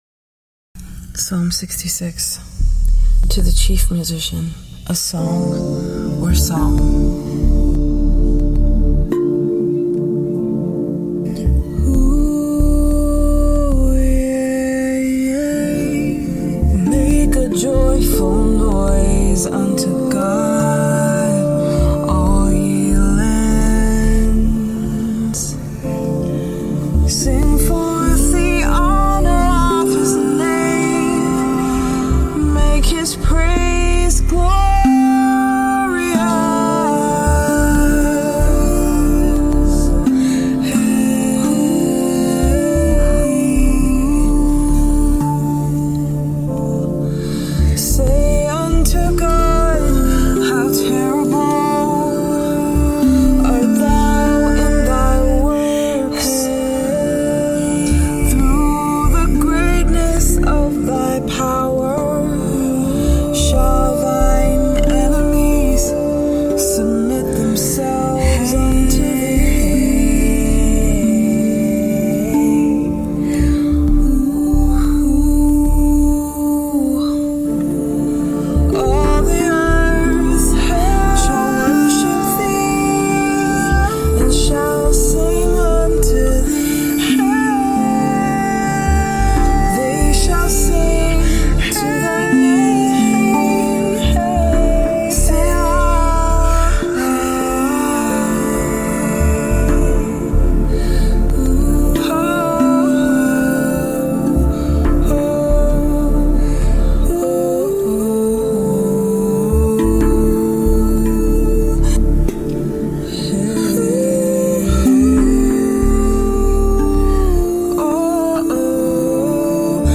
Rav vast drum
tongue drum
worship